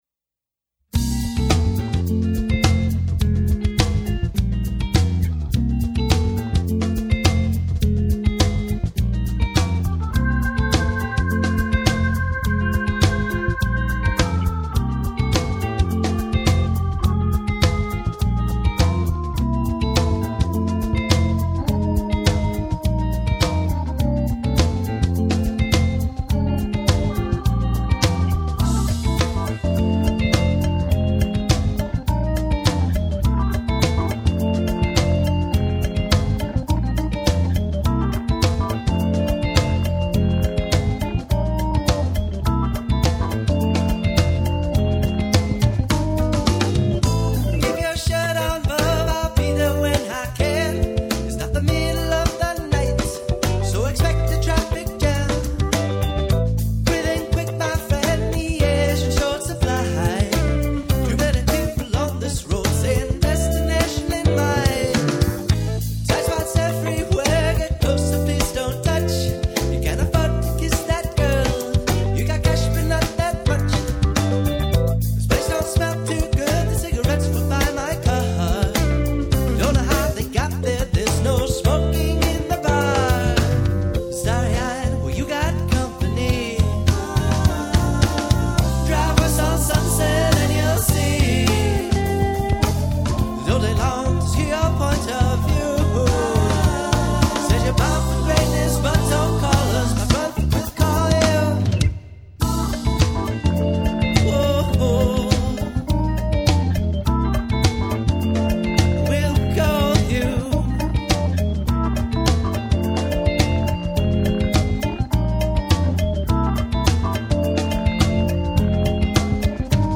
Recorded December 2005 at Studio Chicago